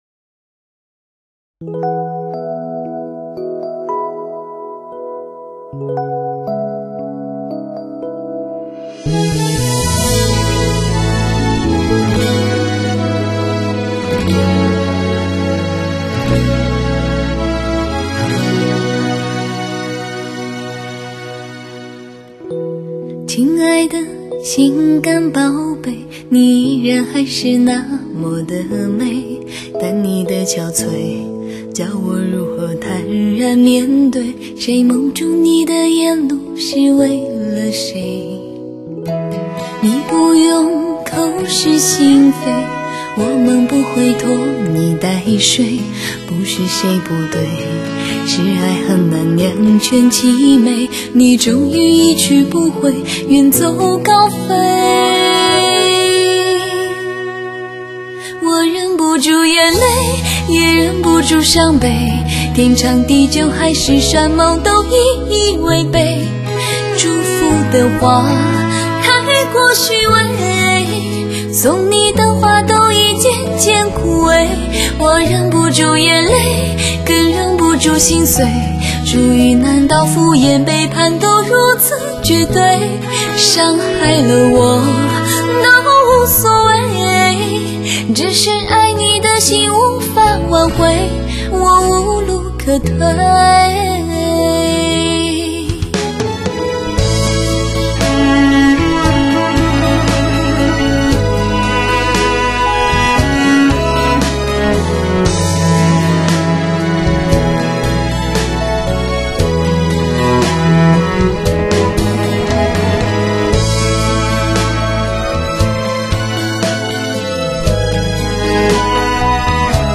清新亮丽  甘之如怡  娓娓唱来
深情甜美收放自如  高原天籁沁人心脾 倾情创新发烧经典之作